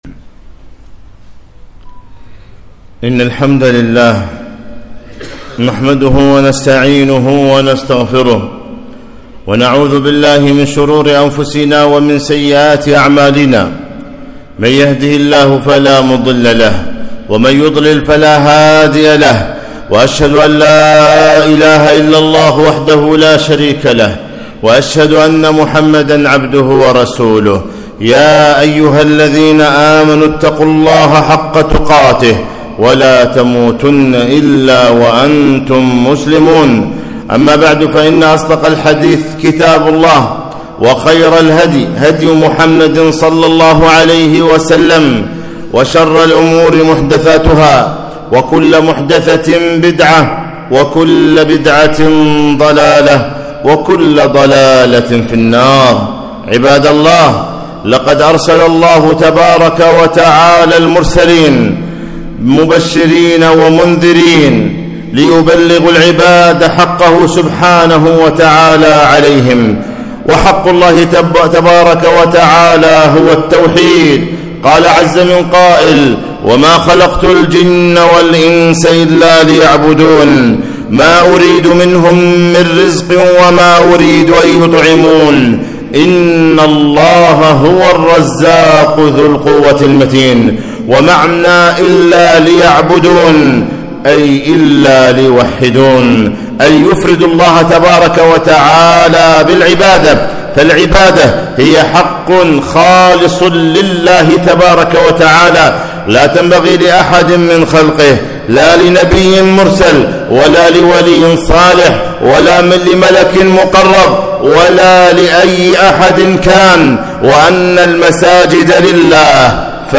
خطبة - الخوف من الشرك الأصغر